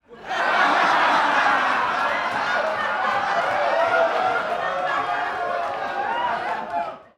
laughter 01